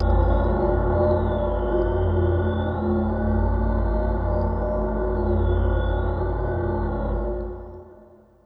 FX-IndustrialNites.wav